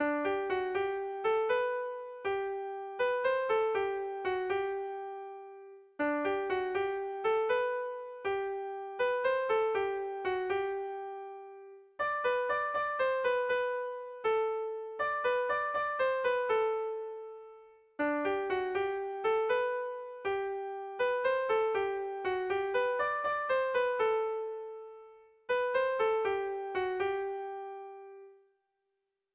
Kontakizunezkoa
Hamarreko txikia (hg) / Bost puntuko txikia (ip)
AABAD